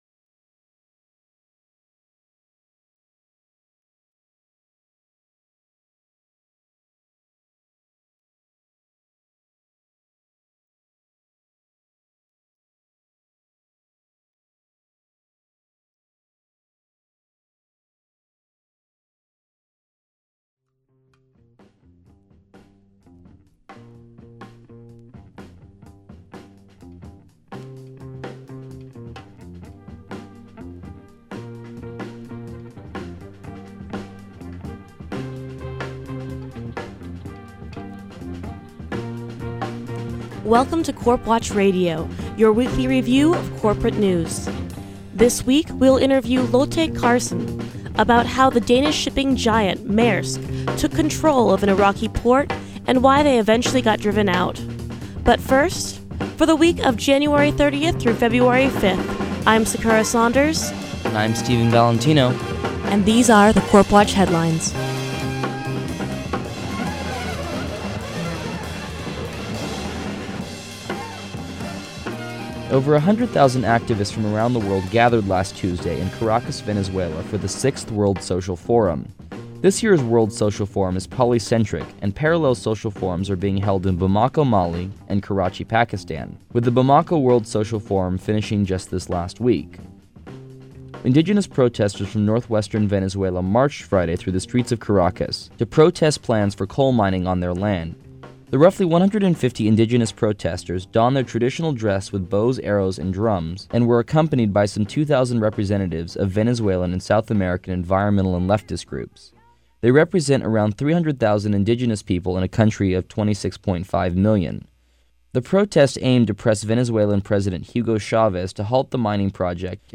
CorpWatch radio is a weekly review and contextualization of the week in Corporate news. This week includes: Enron, Mining in Indonesia, the Privatisation of toll roads, Pharmaceutical gifts, the trafficking of third world labor in Iraq, and much more.